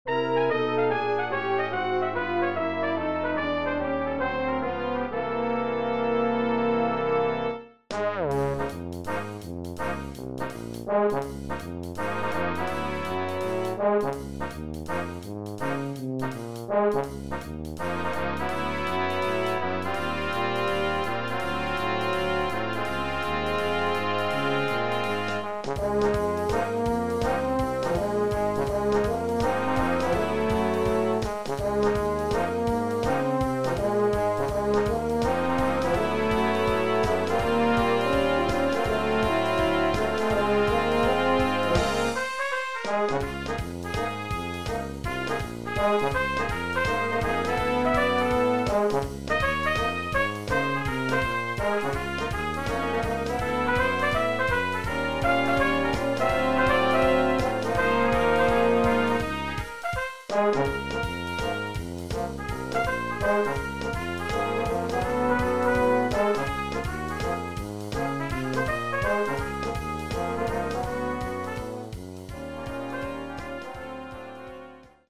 Brass Quintet